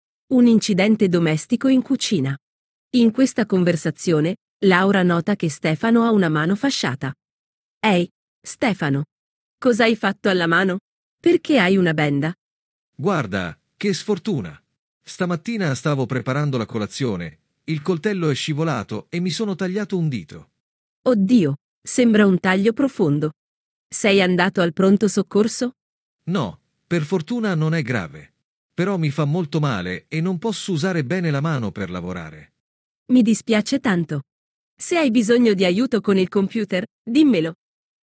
Diálogo 3